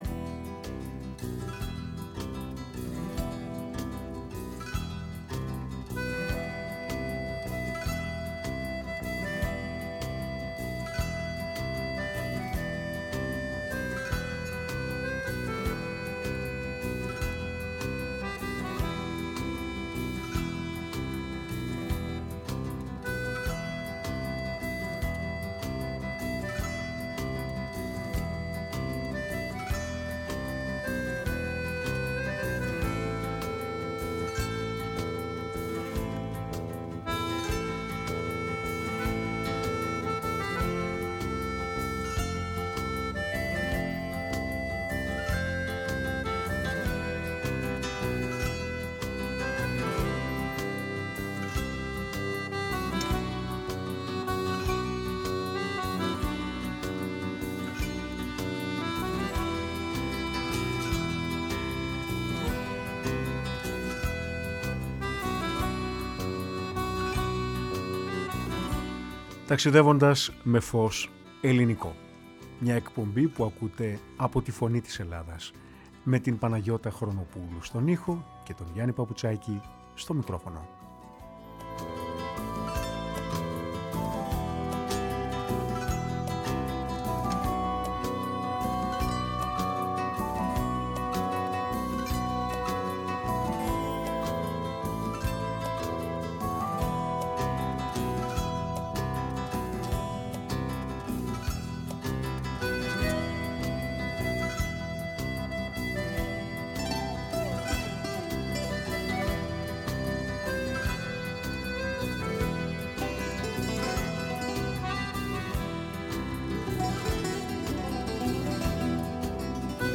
Ακούστηκαν τραγούδια από τις φωνές αγαπημένων ερμηνευτών